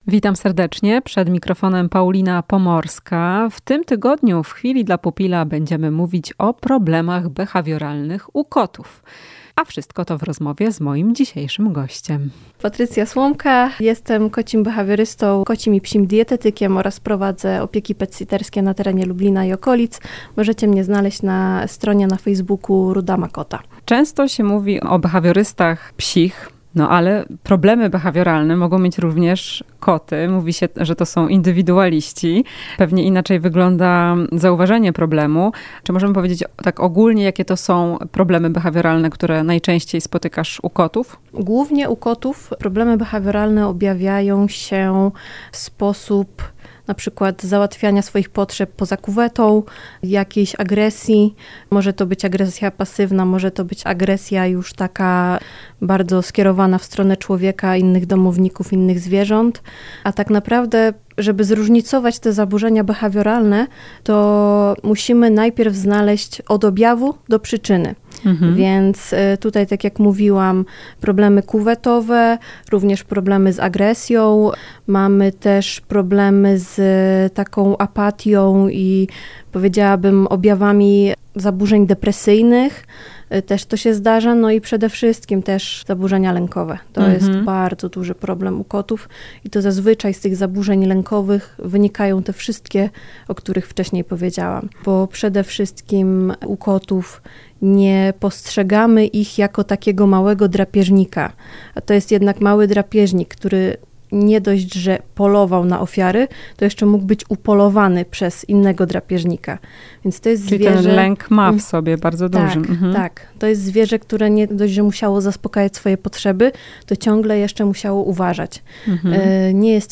W "Chwili dla pupila" omówimy jakie są najczęstsze problemy behawioralne kotów. Rozmowa